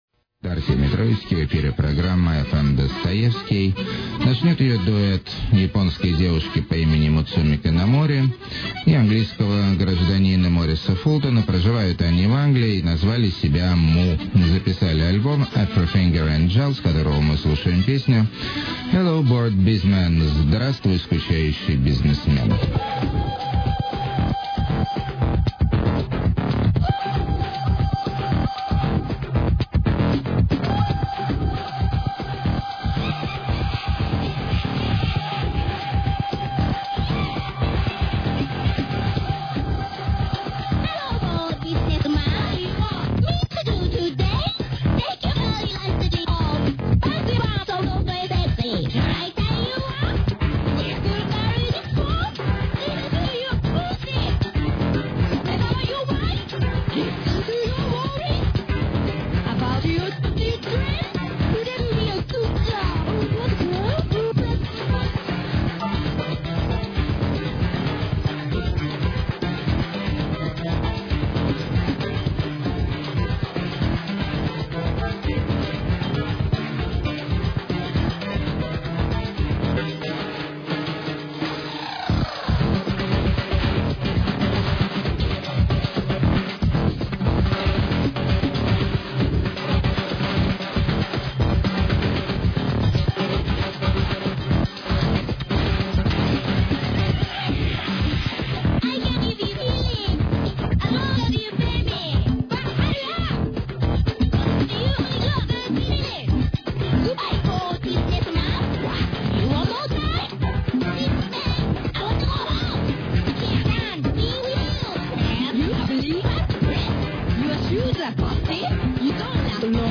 delicate white chanson-hop
epic psycho-pop
alcoholic alterno-country
powerful electric afro
subtle feminine indie rock
post-rock drone maximalism
balkan ethno-fusion